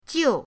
jiu4.mp3